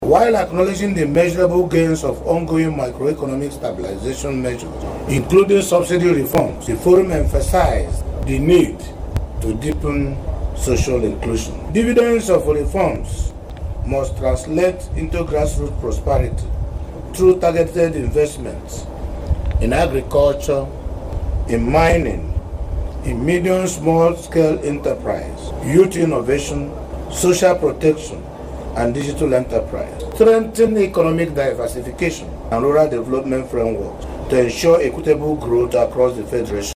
Speaking after a meeting, the chairman of the forum and Imo state governor, Hope Uzodinma stated that while the federal government has shown ingenuity through transformational policies, the benefits of the policies must trickle down to the ordinary citizens.